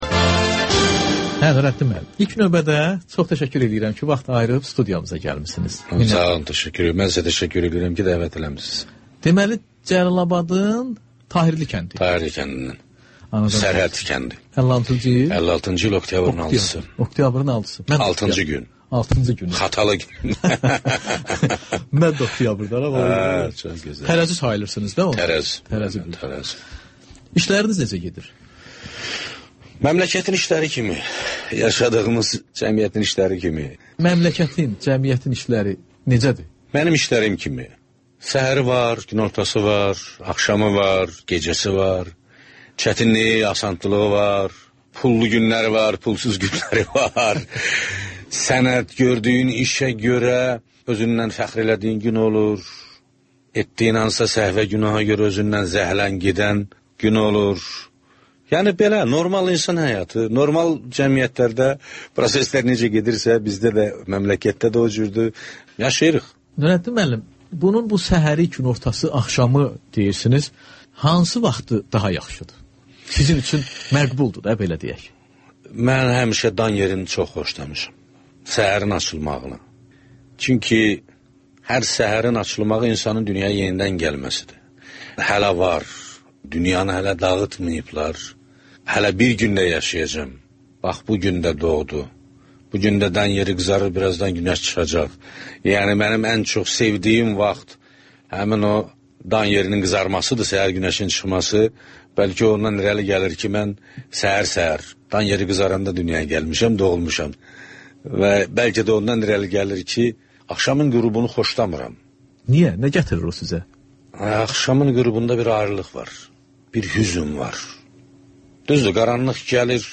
Ölkənin tanınmış simaları ilə söhbət Təkrar